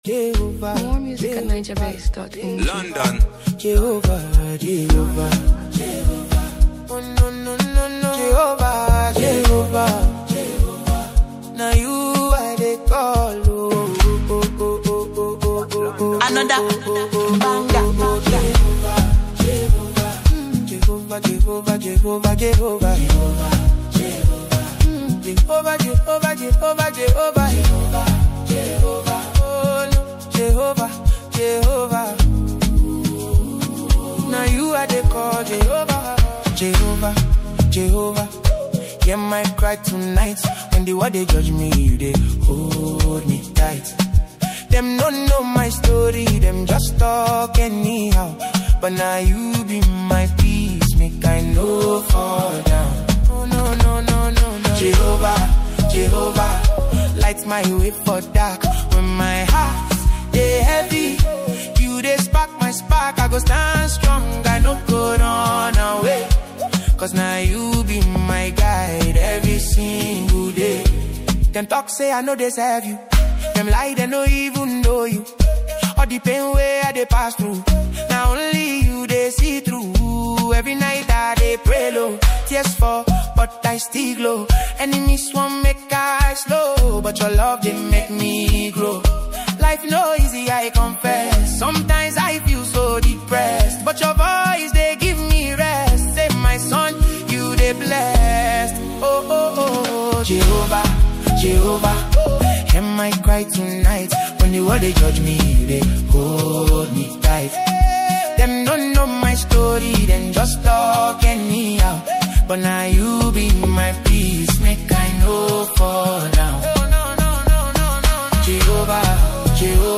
deeply emotional and spiritual song